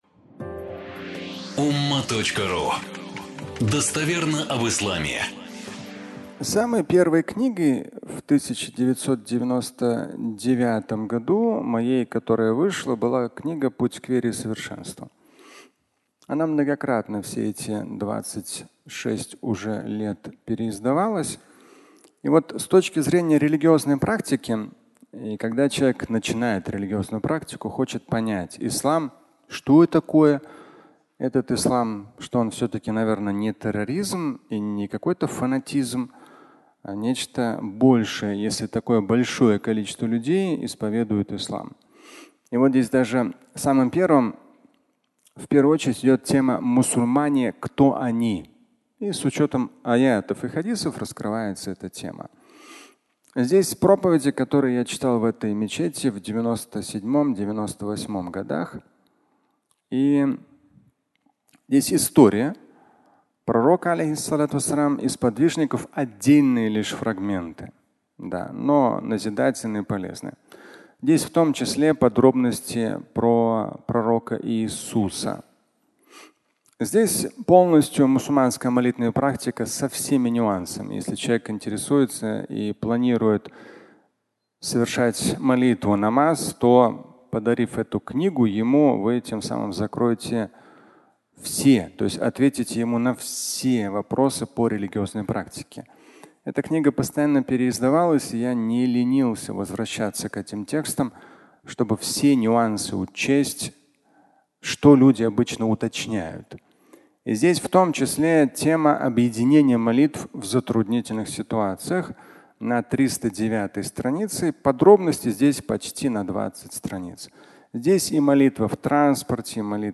Аудио статья
Пятничная проповедь